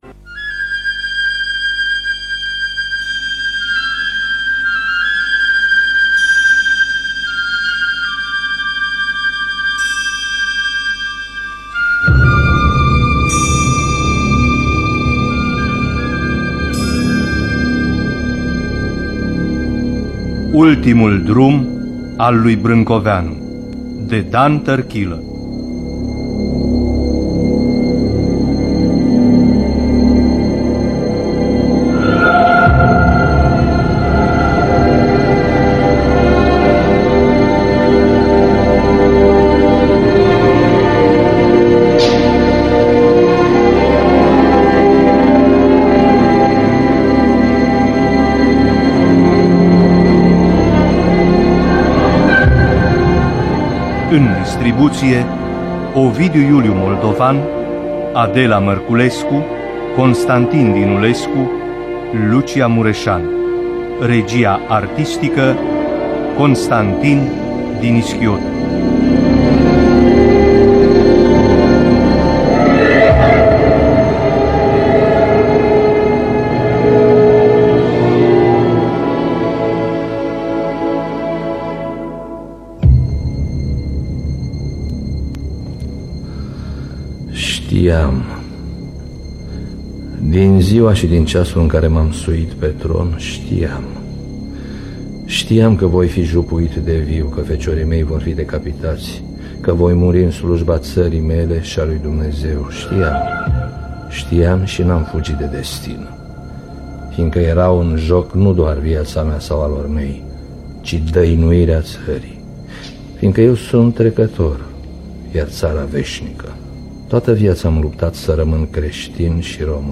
Ultimul drum al lui Brâncoveanu de Dan Tărchilă – Teatru Radiofonic Online